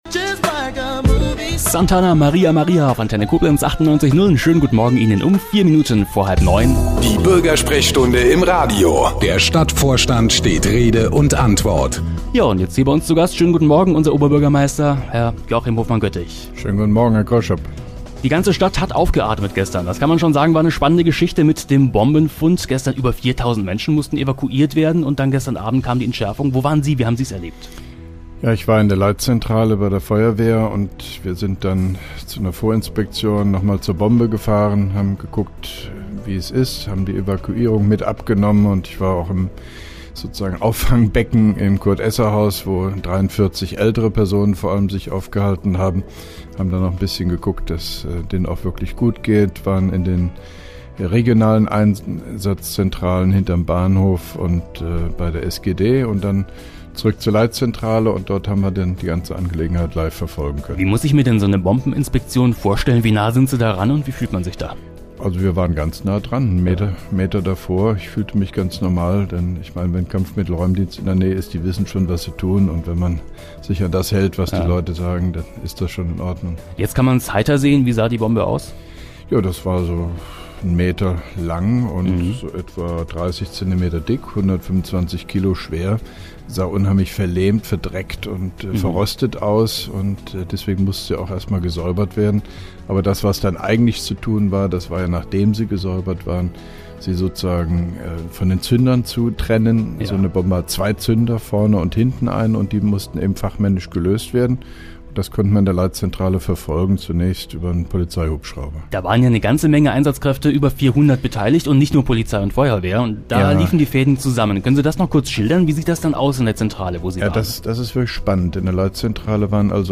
(1) Koblenzer Radio-Bürgersprechstunde mit OB Hofmann-Göttig 26.07.2011
Interviews/Gespräche